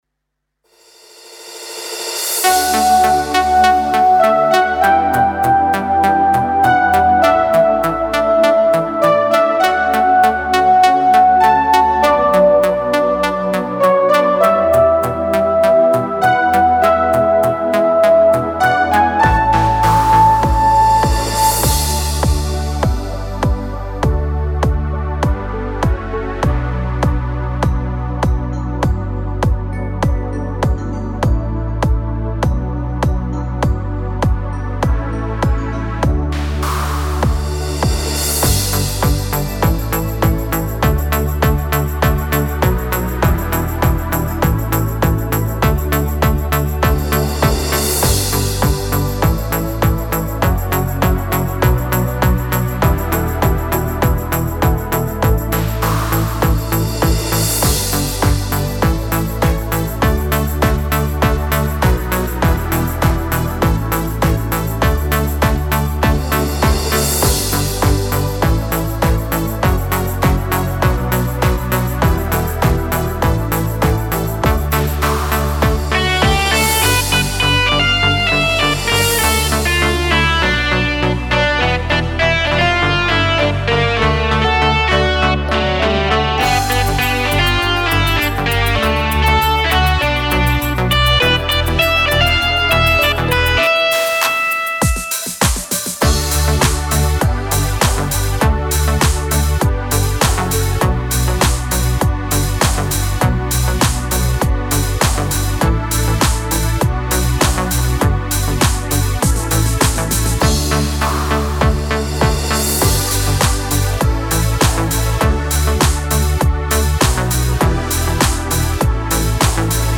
•   Beat  01.